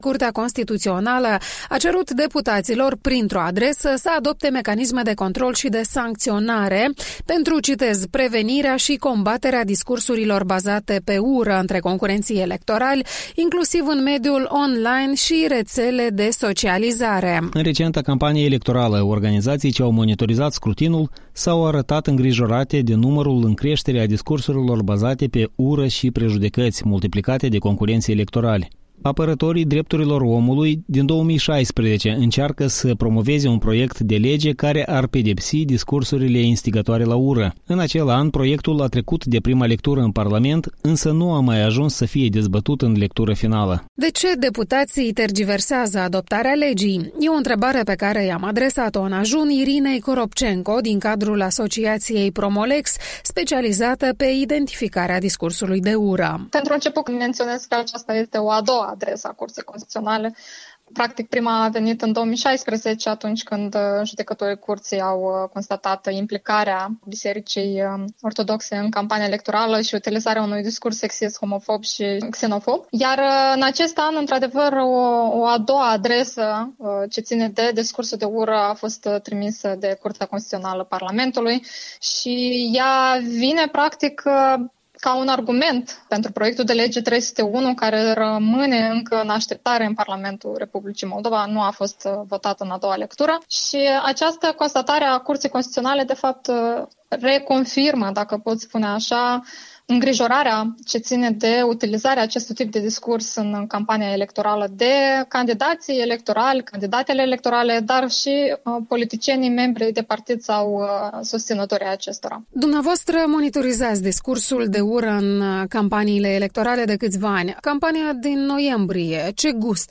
Interviul matinal despre discursul urii